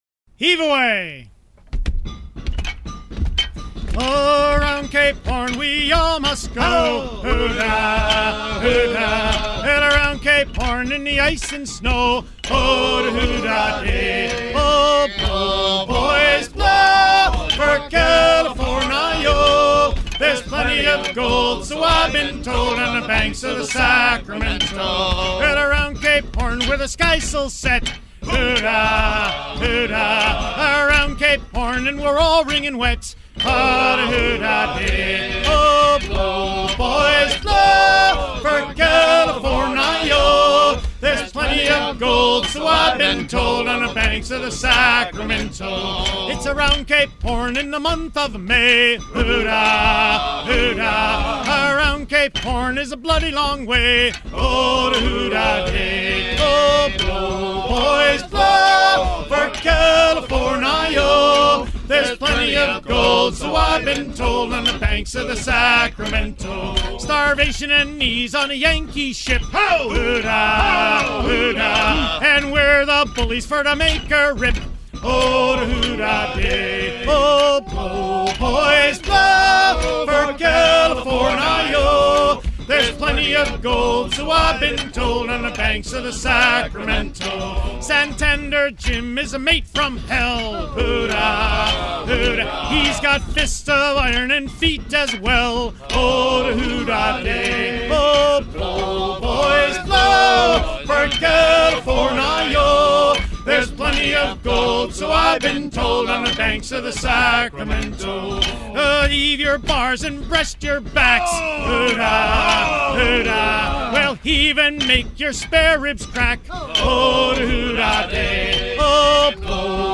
à virer au cabestan
circonstance : maritimes
Pièce musicale éditée